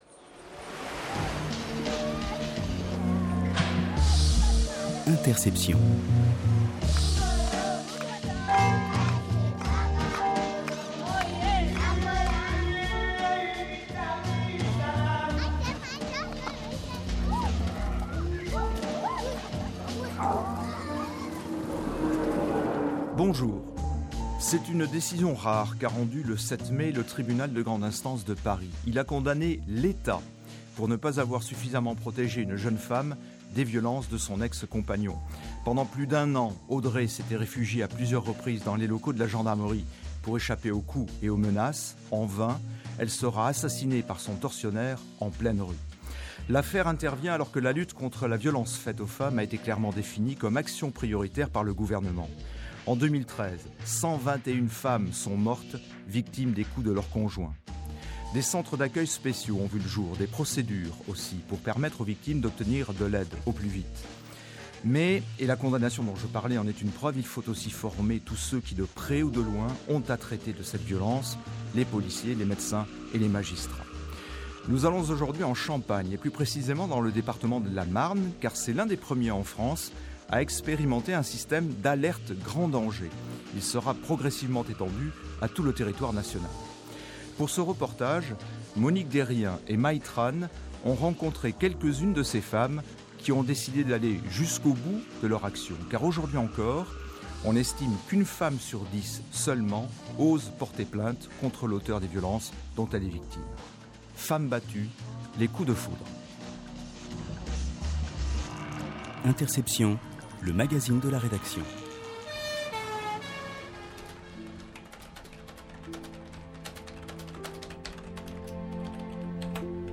Ce reportage nous conduit en Champagne. Le département de la Marne a été l’un des premiers en France à expérimenter un système alerte grand danger.
Nous entendrons aussi des policiers, des magistrats, des travailleurs sociaux, tous unis dans leur action pour endiguer ce fléau.